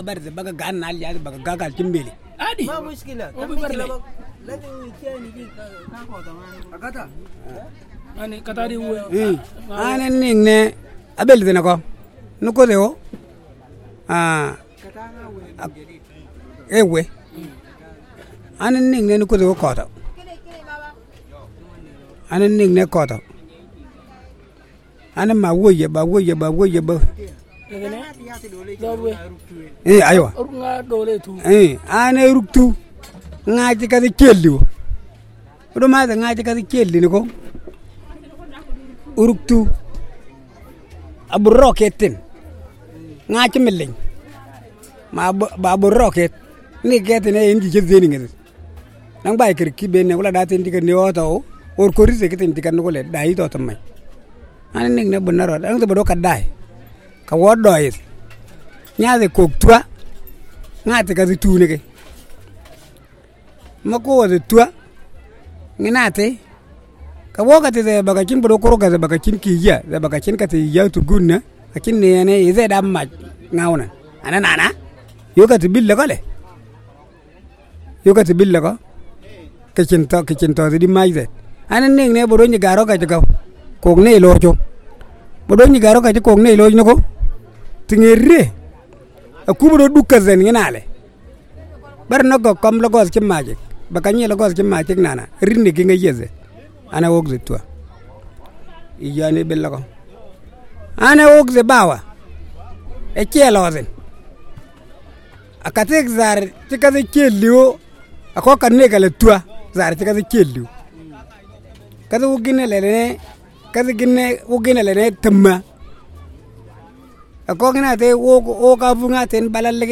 Storytelling Session Details
thestoryofawomanwhoisrejectedbyherhusband_pibor.mp3